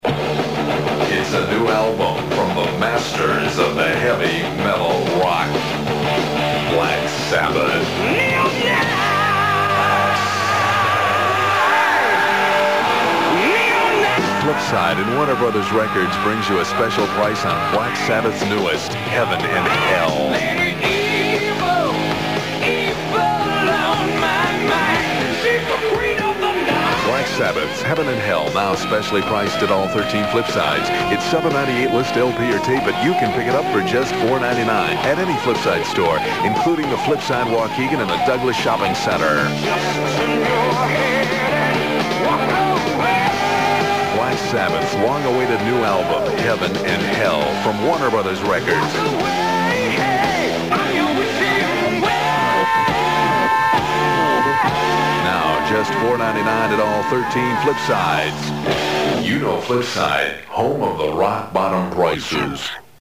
adforheavenandhell.mp3